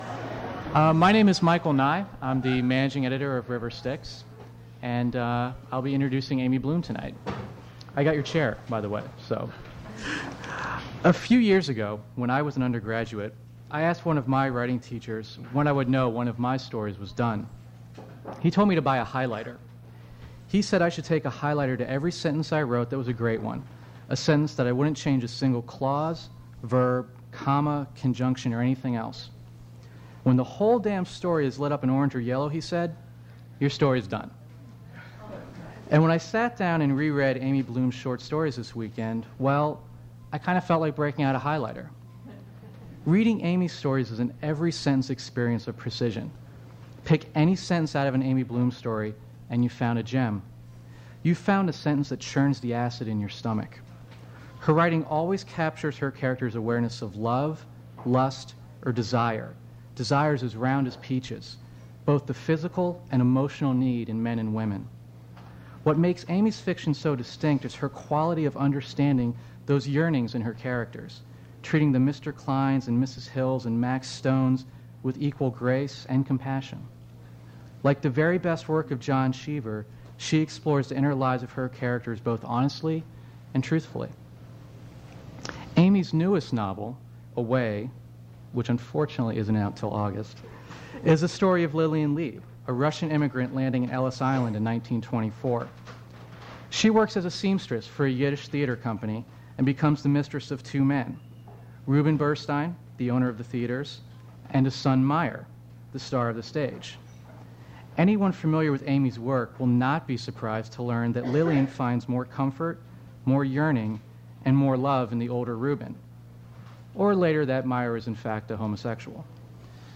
Attributes Attribute Name Values Description Amy Bloom prose reading at Duff's Restaurant. Recording Index: Introduction (0:00); Away (3:58)
Source mp3 edited access file was created from unedited access file which was sourced from preservation WAV file that was generated from original audio cassette.